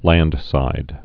(lăndsīd)